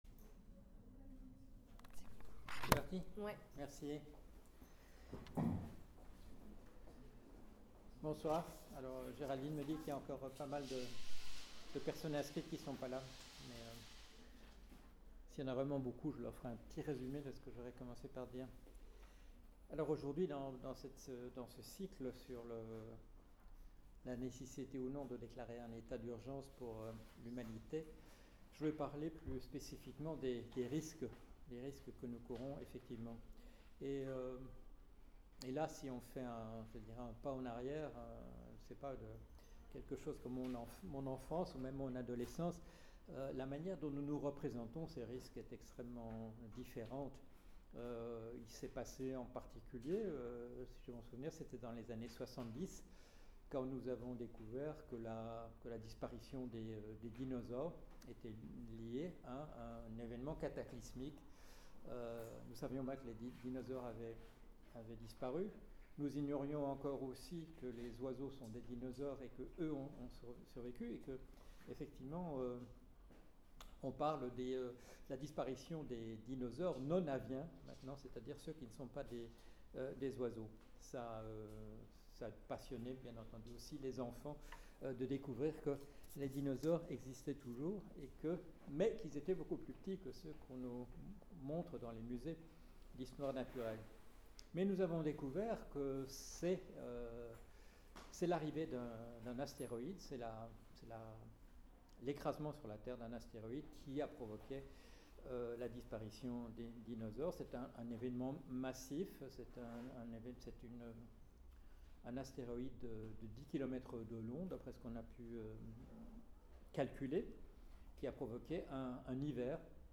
Université Catholique de Lille, Déclarer l’état d’urgence pour le genre humain ? Les risques existentiels pour notre espèce, le 5 février 2019
Conférence tronquée à 26 minutes (fichues piles !) J’aurai cependant eu le temps de répertorier les cataclysmes naturels les plus décourageants, et l’incurie humaine la plus navrante.